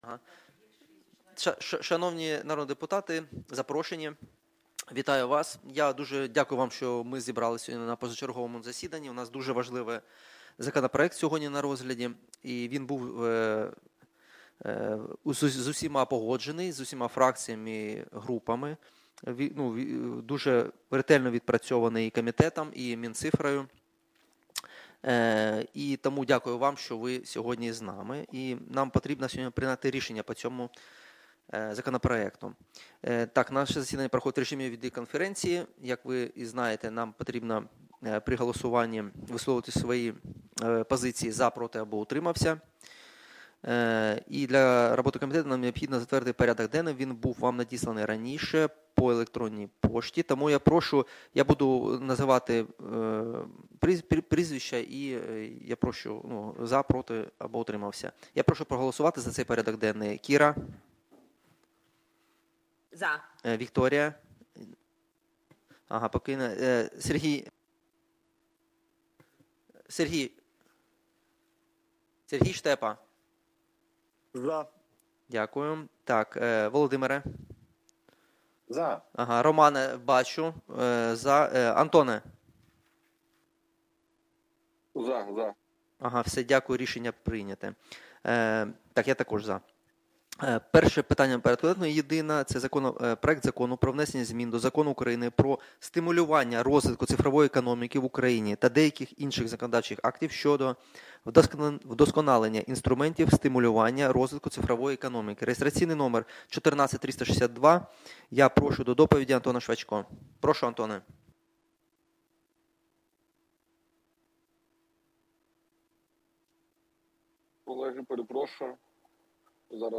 Аудіозапис засідання Комітету від 04.02.2026